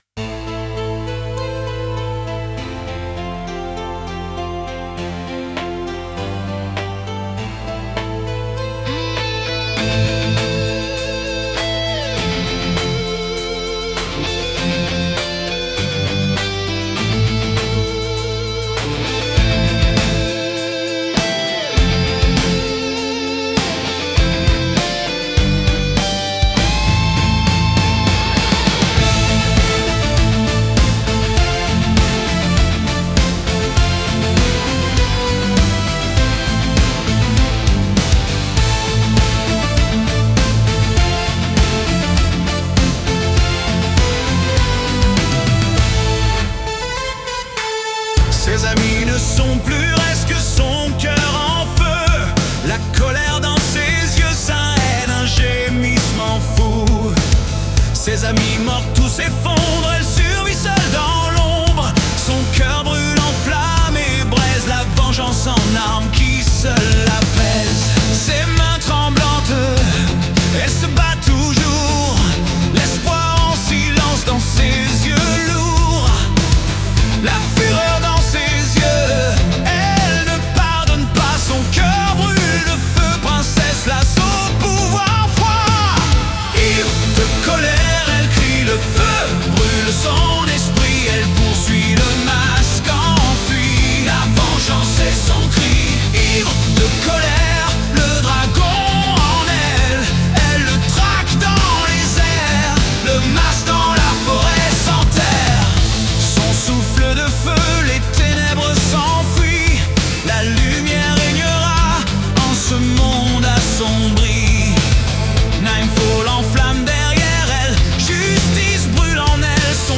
Réalisé sur PC avec Logic Audio.
• Format : ogg (stéréo)